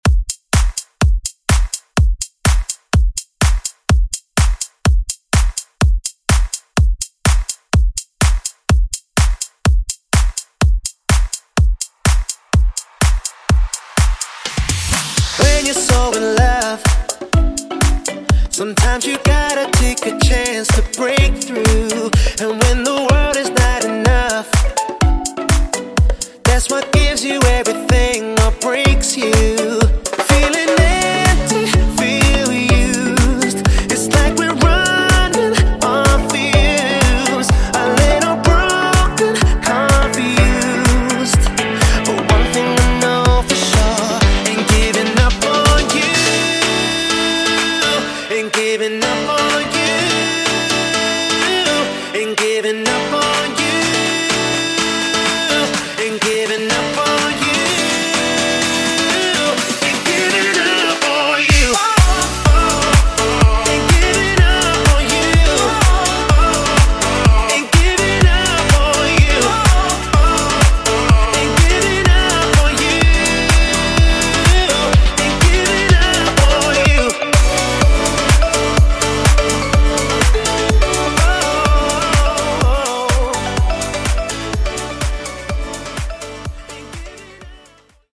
Club ReDrum)Date Added